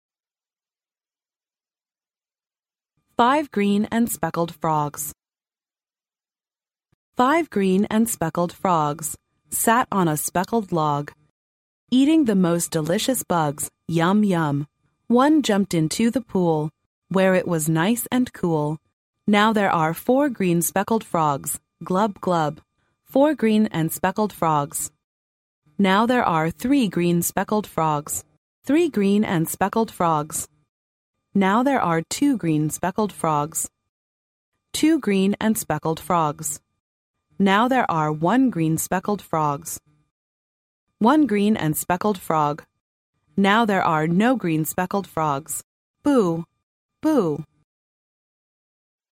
幼儿英语童谣朗读 第37期:五只绿色斑点蛙 听力文件下载—在线英语听力室